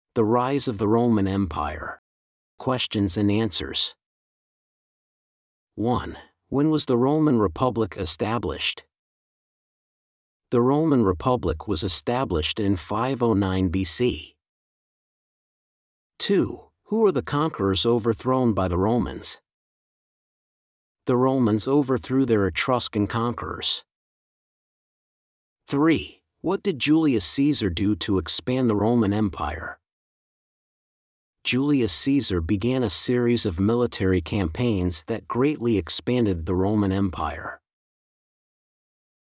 Two MP3s and a transcript: A narration about the event and a factual Q&A segment.